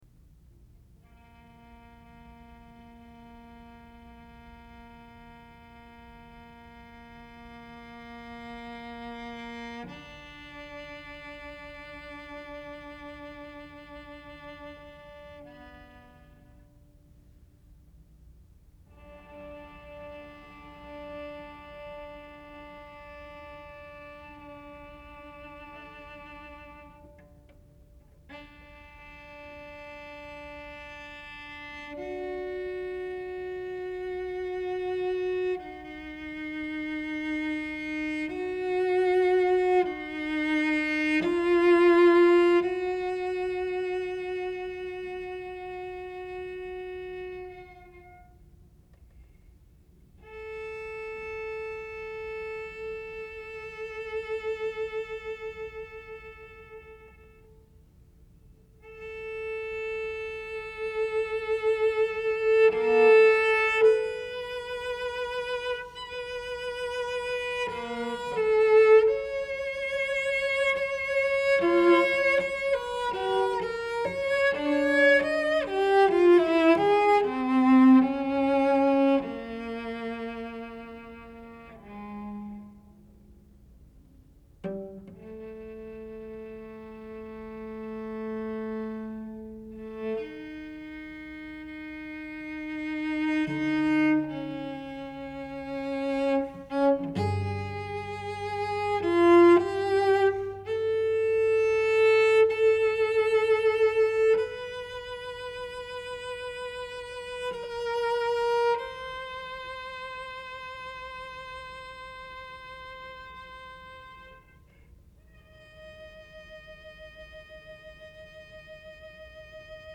Violoncello Solo